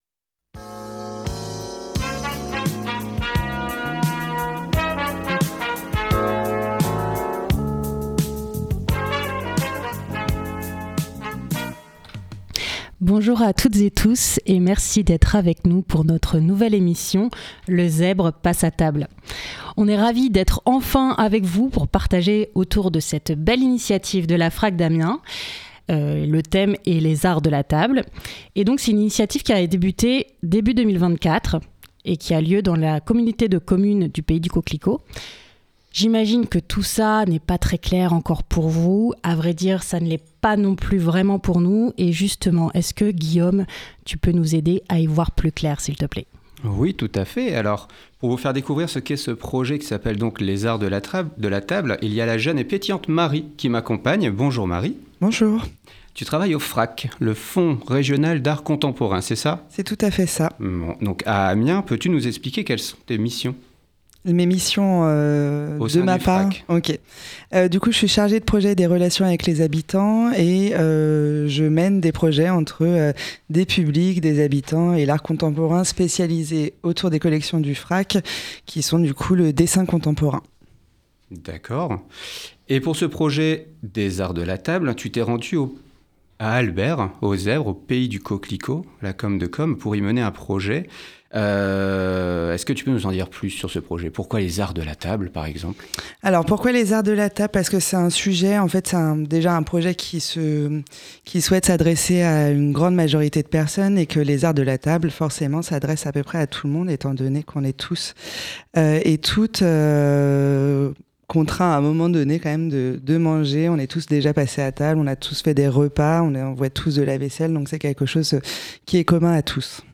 Le Frac Picardie et les habitants du pays du coquelicot vous proposent une émission autour des arts culinaires.
Au programme : musiques culinaires, anecdotes gustatives, et de quoi compléter votre livre de recettes !